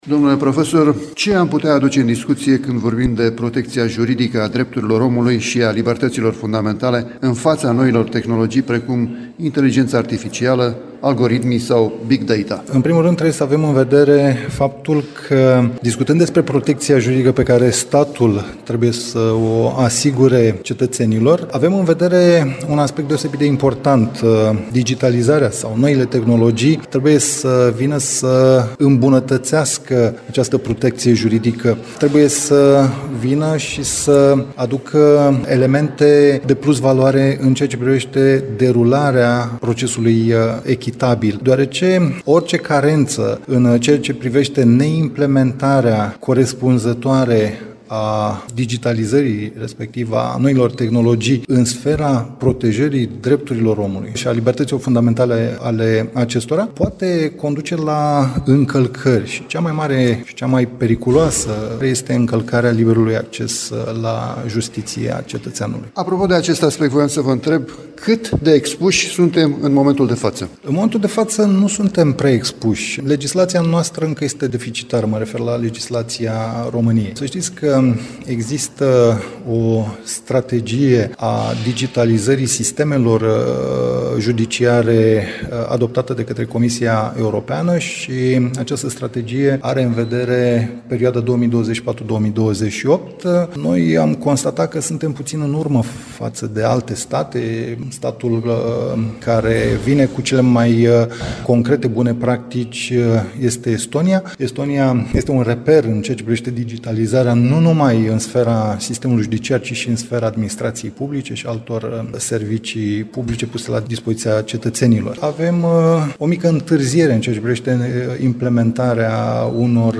Această temă a protecției juridice a fost abordată și la Constanța, în cadrul unei conferințe stiințifice naționale a profesiilor juridice